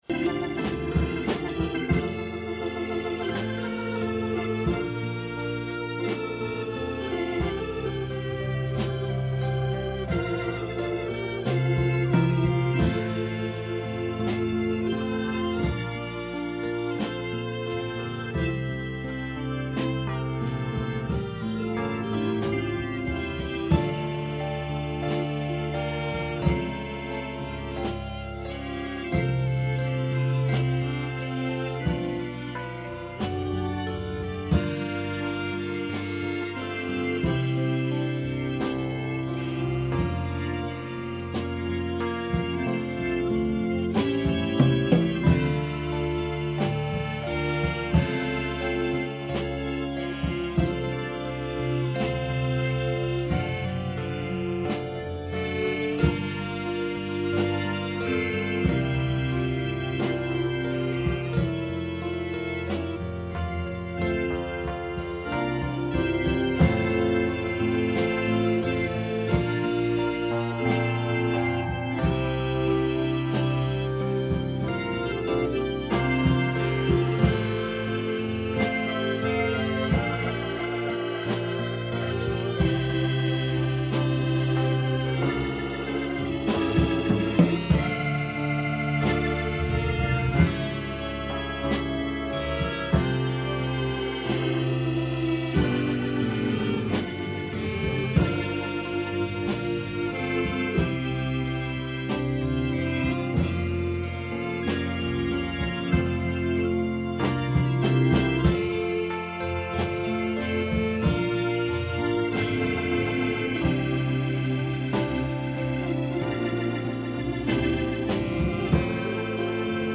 John 14:16-21 Service Type: Sunday Morning %todo_render% « Teaching on The Holy Ghost